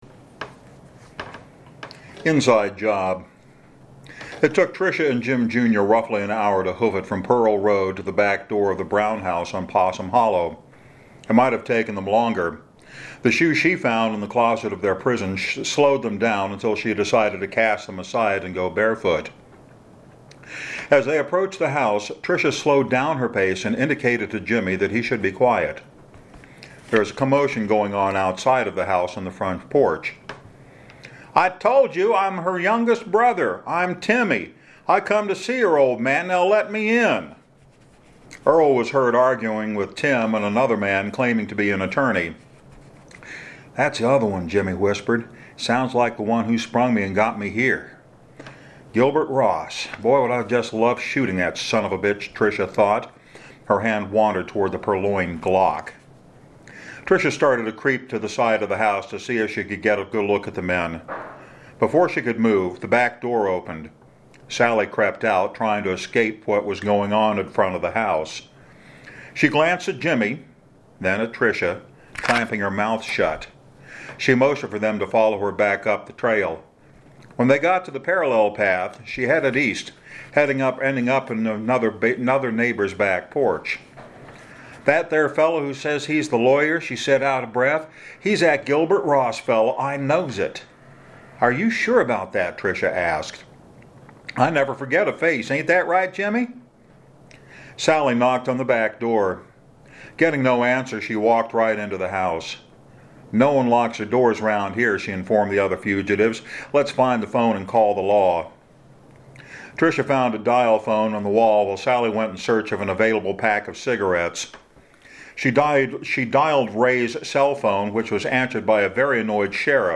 The audio version will be different in that edits will be made to eliminate the little flaws in pronunciation this version has in spades.